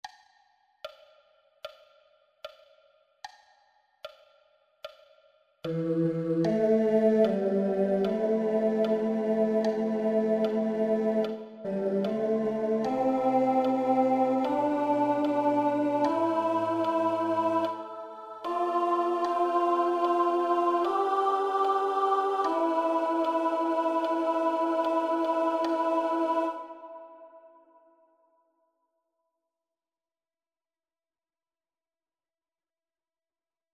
Key written in: A Minor